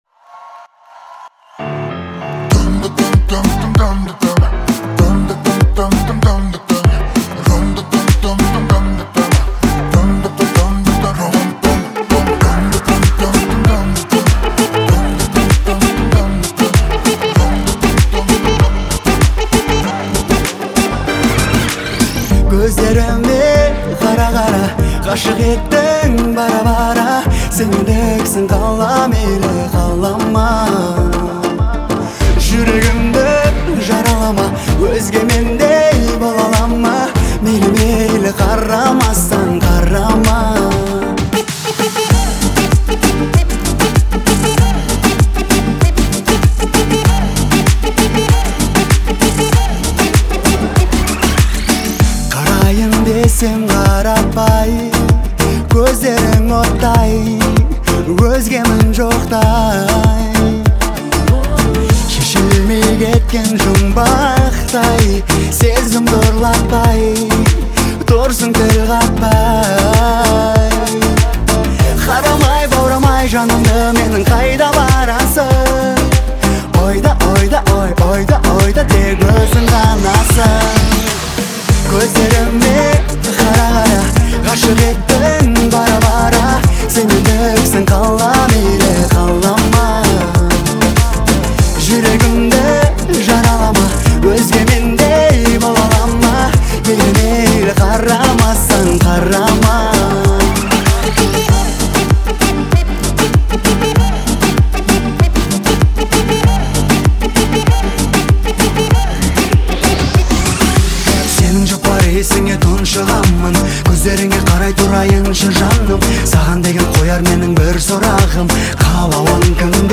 это энергичная композиция в жанре поп-музыки